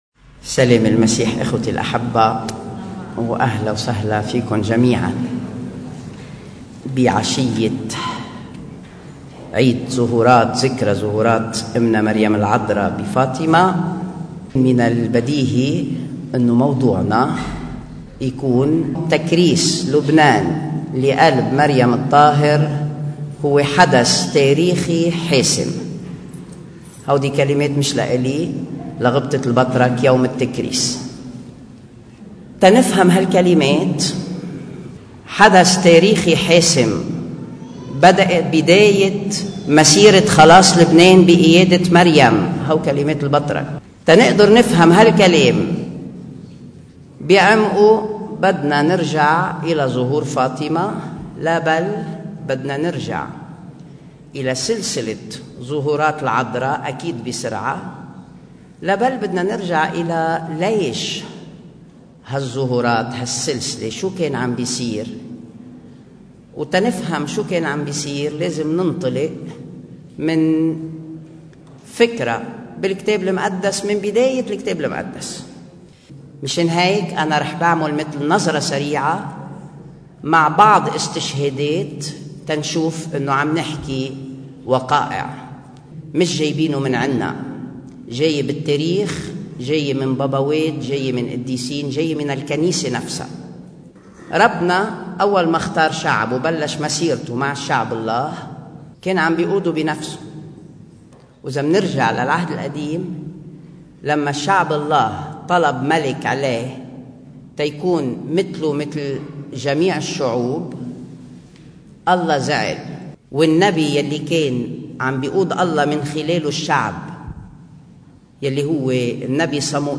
مقتطف من عظة